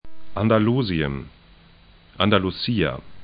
Andalusien anda'lu:zĭən Andalucía andalu'si:a